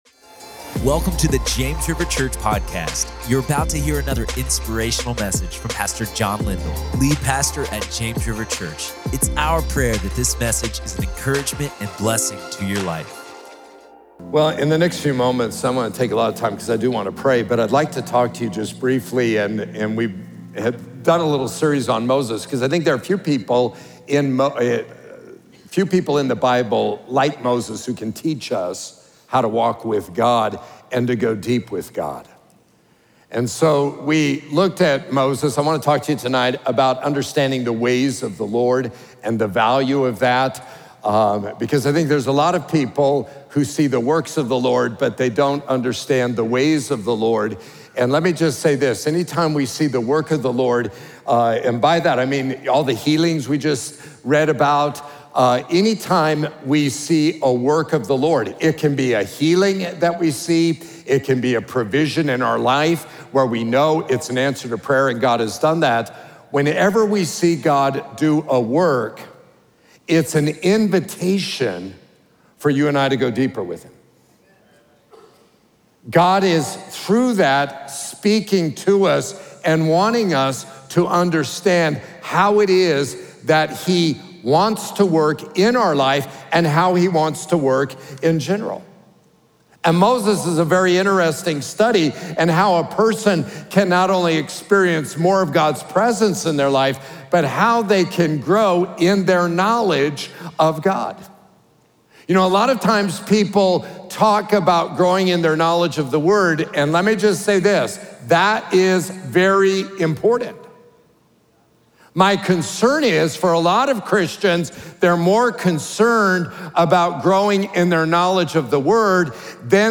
Knowing the Ways of the Lord | Prayer Meeting